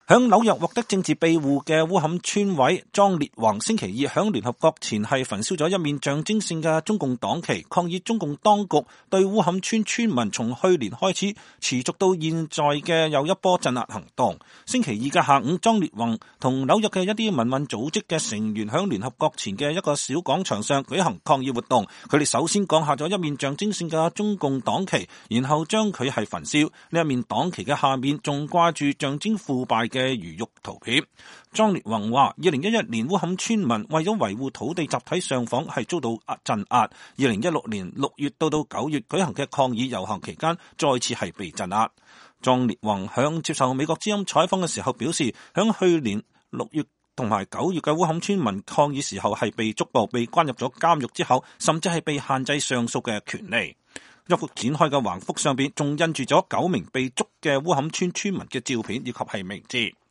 在紐約聯合國大樓前的抗議者。